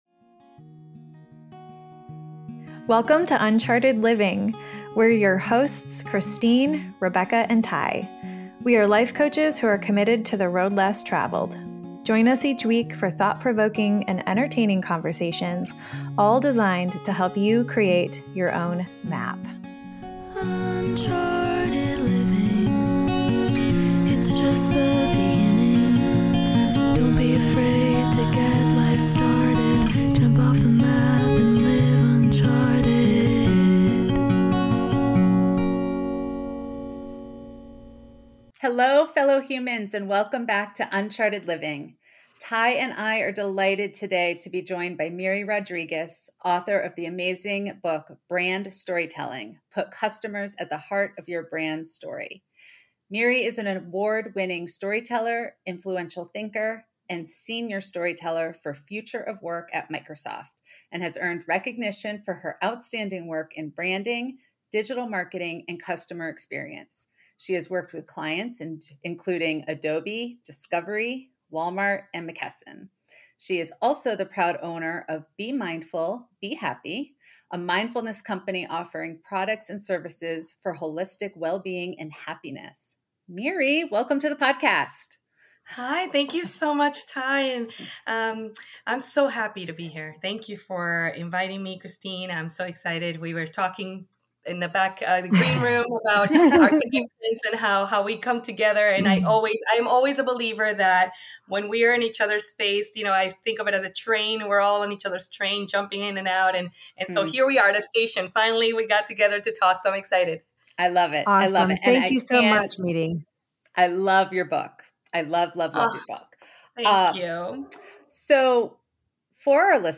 We hope you enjoy this conversation as much as we did.